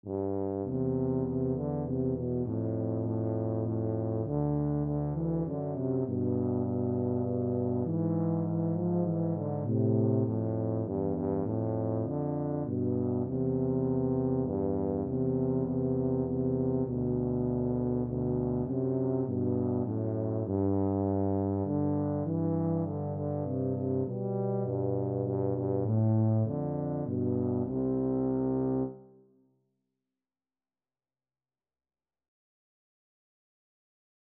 "We Wish You a Merry Christmas" is a popular sixteenth-century English carol from the West Country of England.
C major (Sounding Pitch) (View more C major Music for Tuba Duet )
3/4 (View more 3/4 Music)
Moderato
Tuba Duet  (View more Easy Tuba Duet Music)